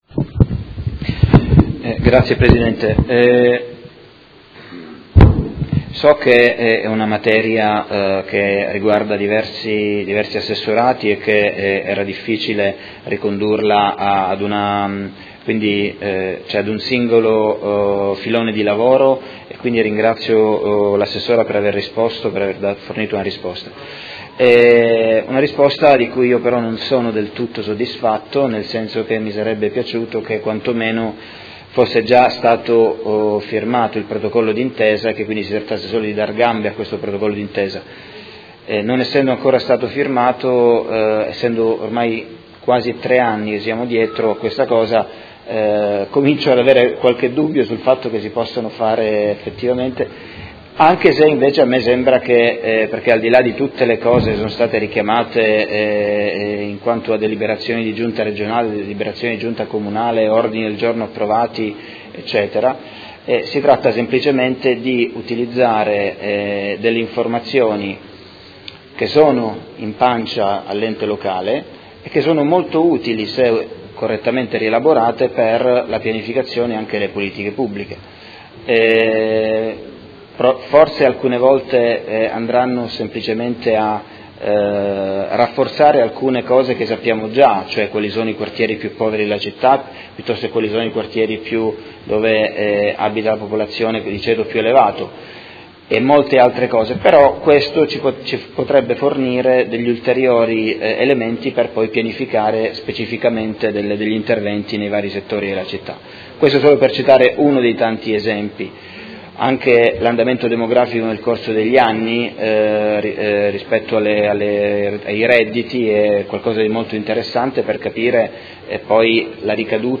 Seduta del 28/03/2019. Conclude interrogazione del Consigliere Fasano (PD) avente per oggetto: Condivisone di banche dati amministrative e relative ricerche sulle condizioni socio-economiche dei cittadini modenesi